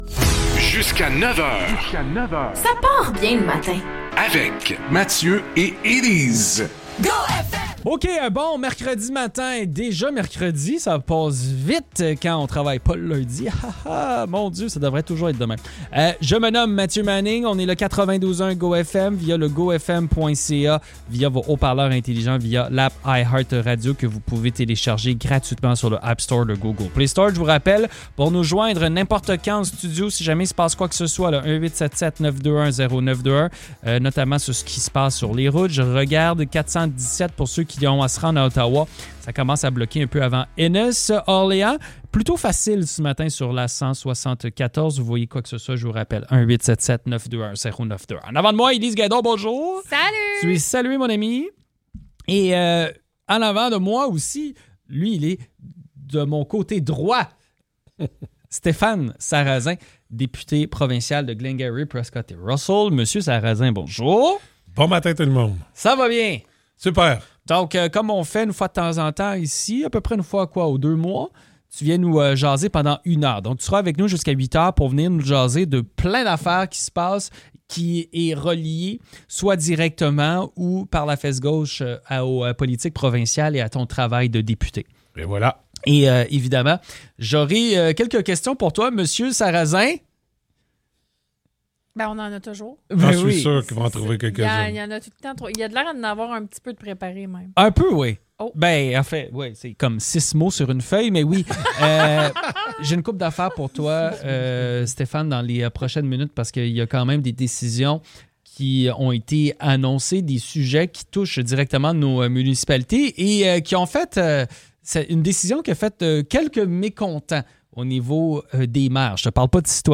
Le député provincial de Glengarry–Prescott–Russell, Stéphane Sarrazin, est venu passer une heure avec nous en studio.